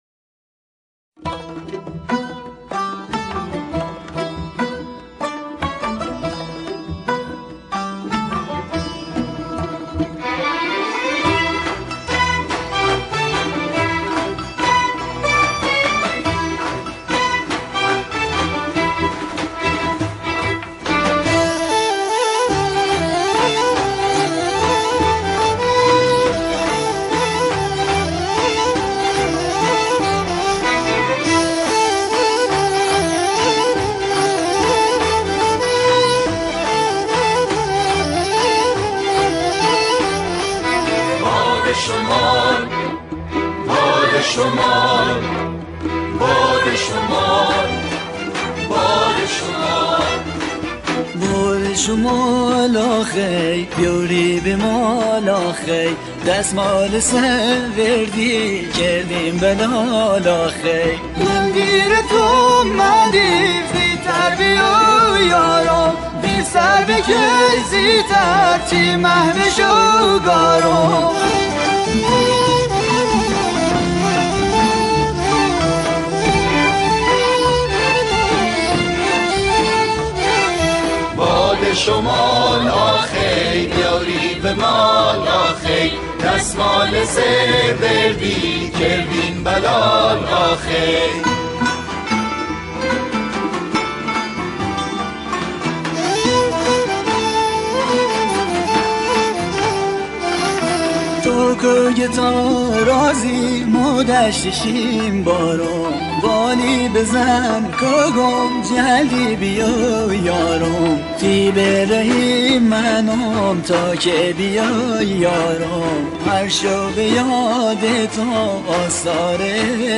گروهی از همخوانان
با گویش بختیاری